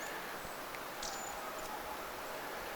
tuollainen peukaloislinnun ääni
tuollainen_ilm_peukaloislinnun_aani.mp3